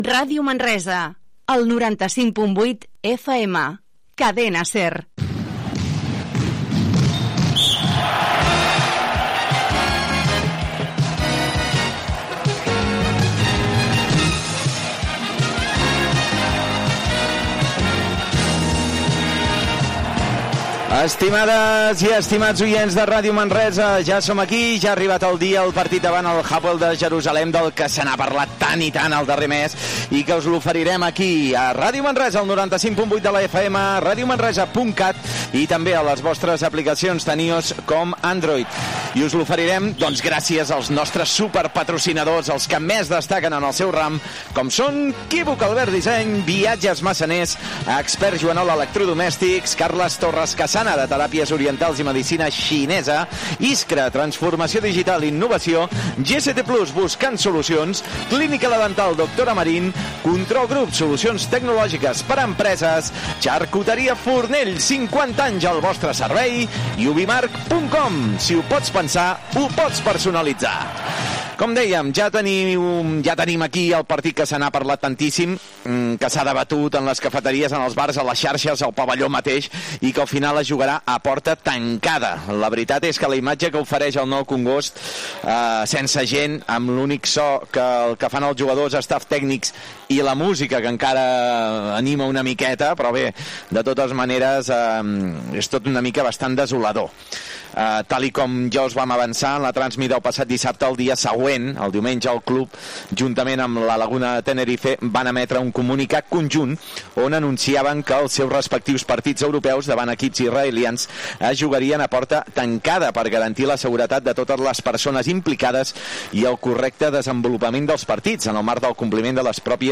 Indicatiu de la ràdio, sintonia, patrocinadors, transmissió del partit de bàsquet de l'Euro Cup entre el Baxi Manresa i l'Hapoel Bank Yahav Jerusalem, que se celebra al pavelló Nou Congost de Manresa a porta tancada, Prèvia del partit i narració de les jugades del primer quart Gènere radiofònic Esportiu